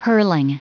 Prononciation du mot hurling en anglais (fichier audio)
Prononciation du mot : hurling